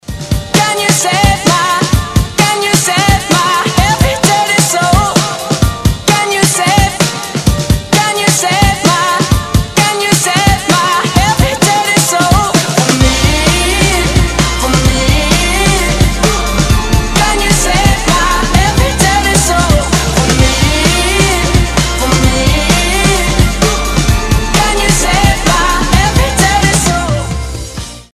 громкие
заводные
dance
alternative
Indie